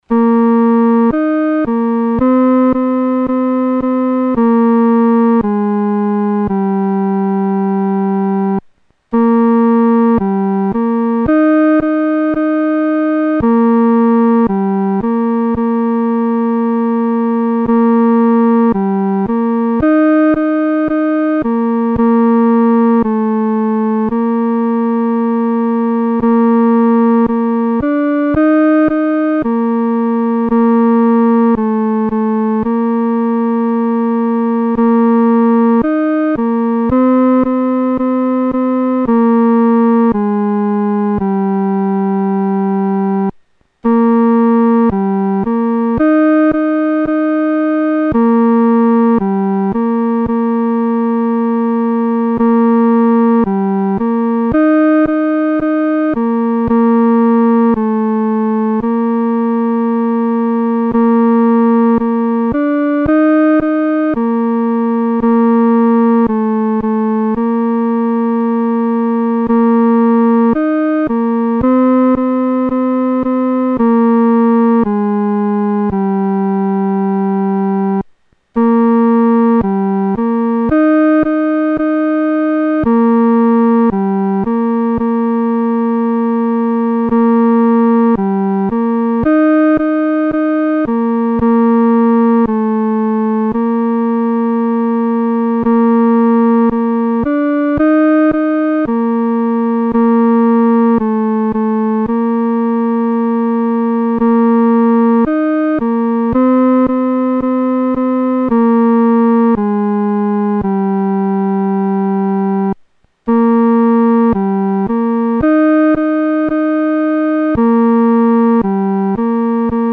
独奏（第三声）
求主掰开生命之饼-独奏（第三声）.mp3